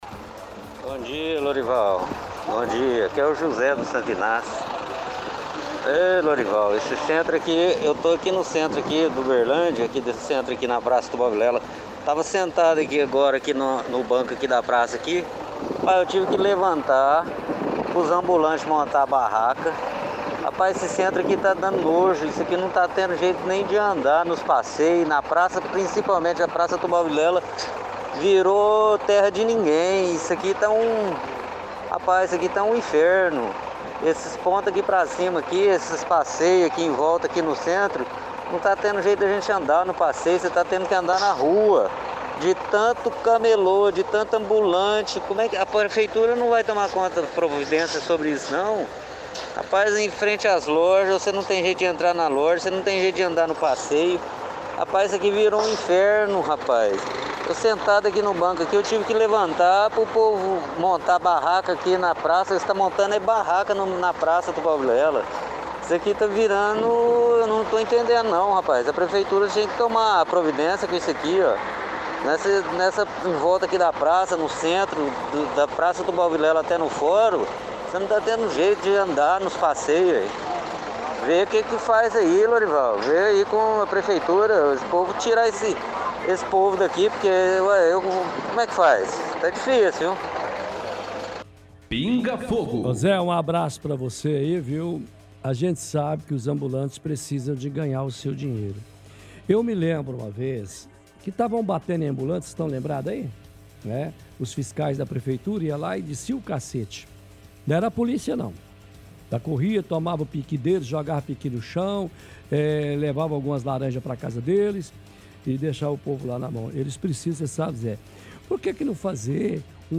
– Ouvinte reclama da quantidade de ambulantes no centro da cidade. Pede que a prefeitura tome providencias.